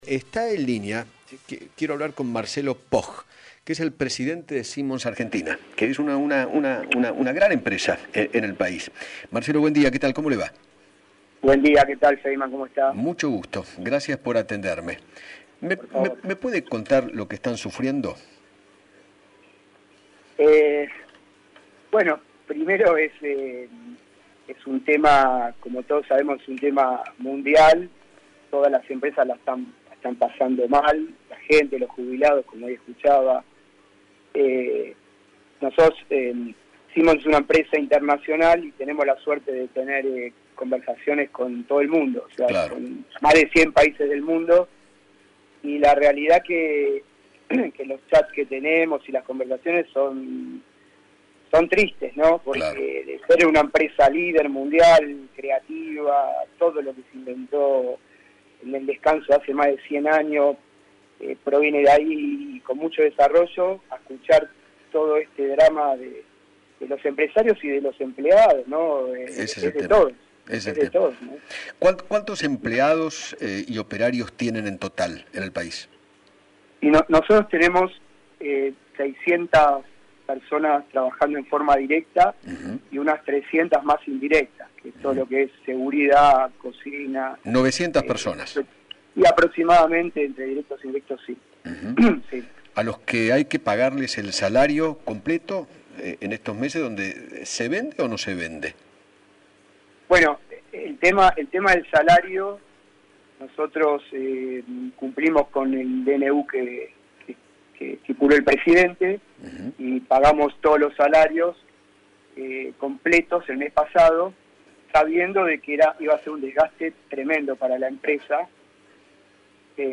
dialogó con Eduardo Feinmann sobre el impacto de la cuarentena en las pymes y en la insdutria.